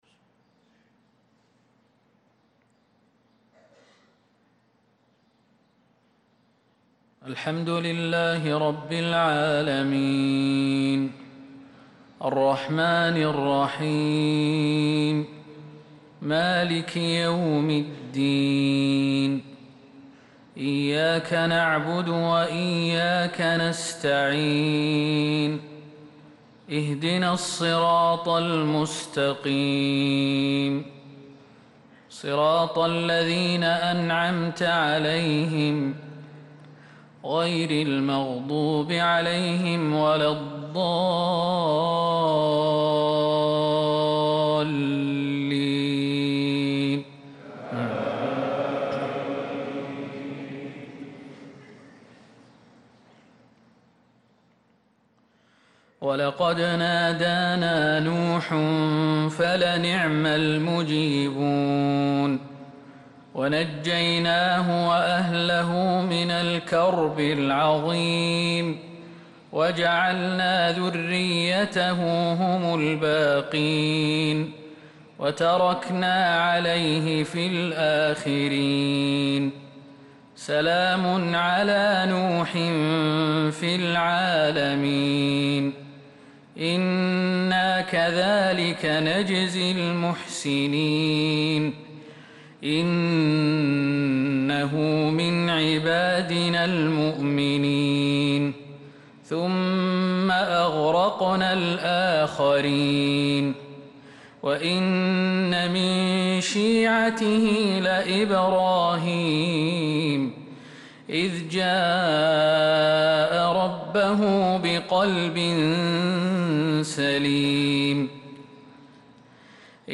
صلاة الفجر للقارئ خالد المهنا 12 ذو الحجة 1445 هـ